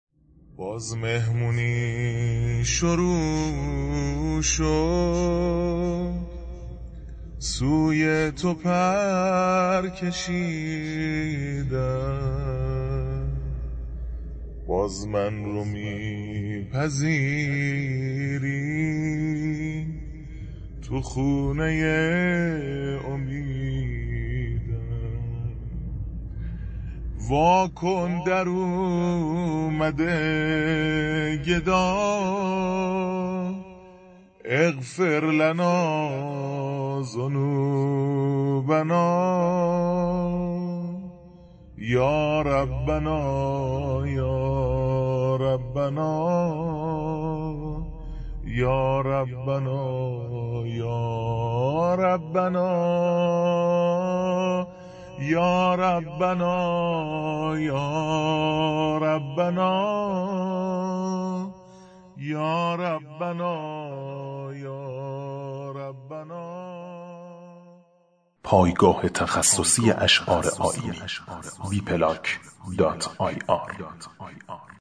مناجات
زمزمه